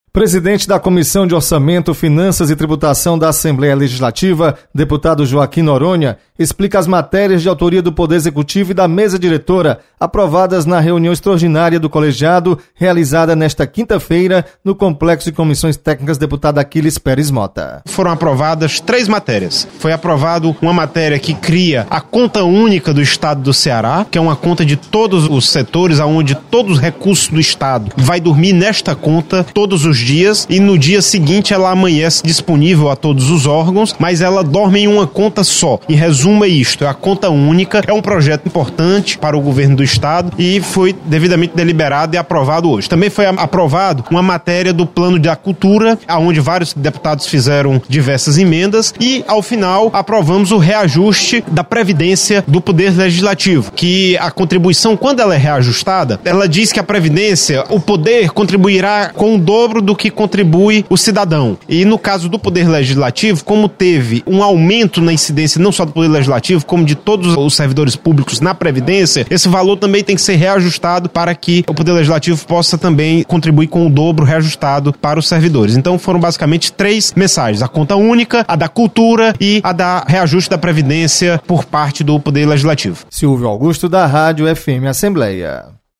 CCJ realiza última reunião e do primeiro semestre de 2017. Repórter